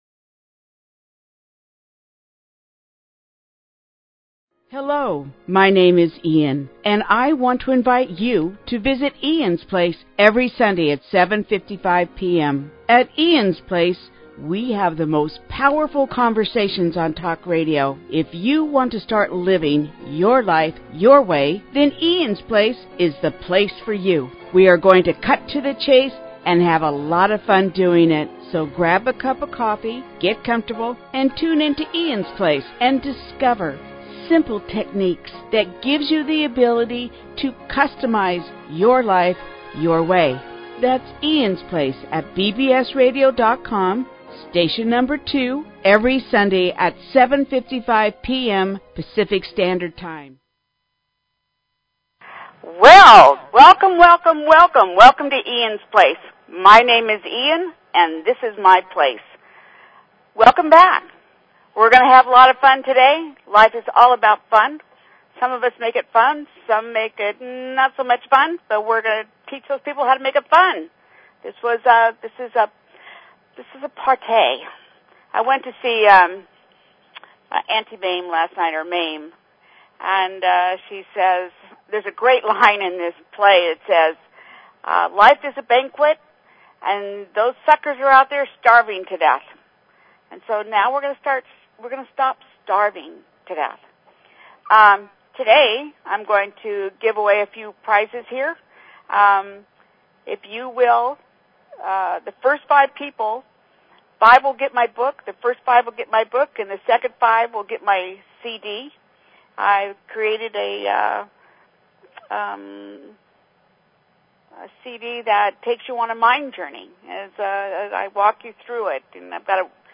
Talk Show Episode, Audio Podcast, Eans_Place and Courtesy of BBS Radio on , show guests , about , categorized as